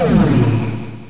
powerup.mp3